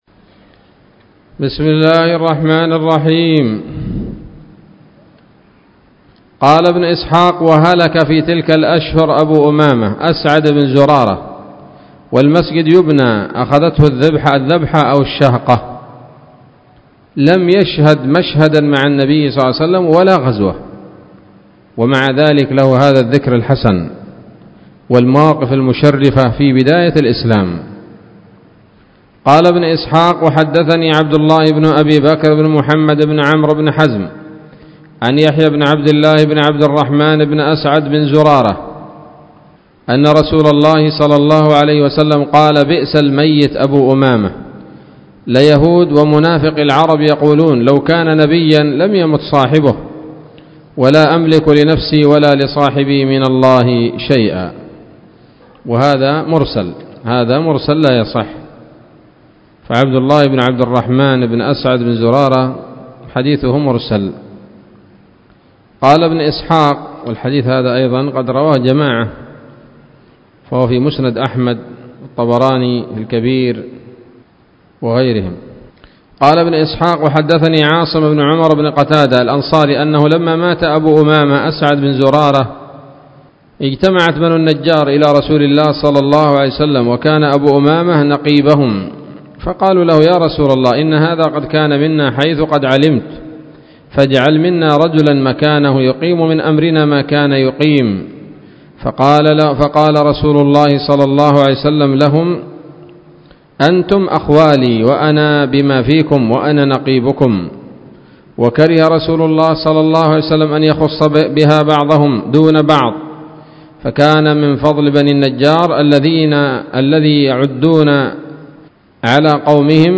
الدرس الثمانون من التعليق على كتاب السيرة النبوية لابن هشام